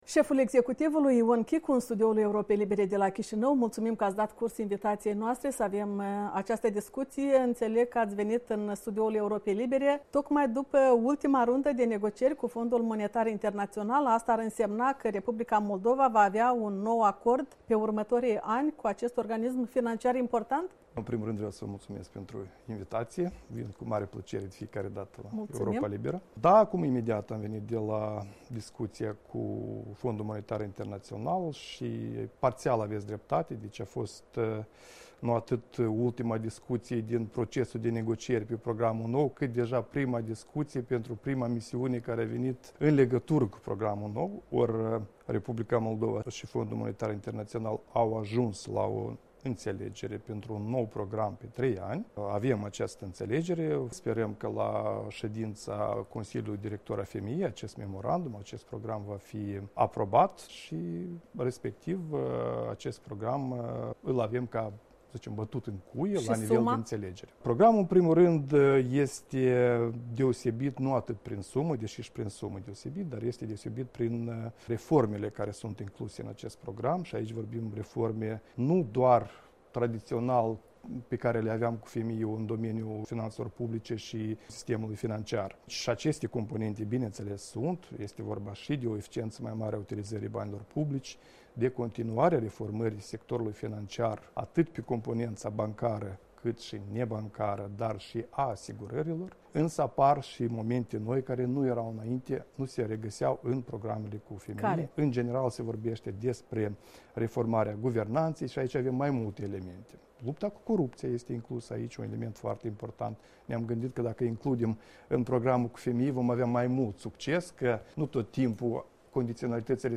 Premierul Ion Chicu a declarat că guvernul pe care îl conduce a finalizat negocierile cu Fondul Monetar Internaţional a unui nou memorandum pentru o perioadă de trei ani. Într-un interviu oferit Europei Libere, șeful cabinetului de miniștri a precizat că este vorba de un împrumut de 550 de...